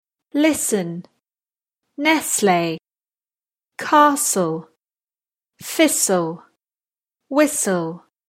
listen-nestle-castle-thistle-whistle.mp3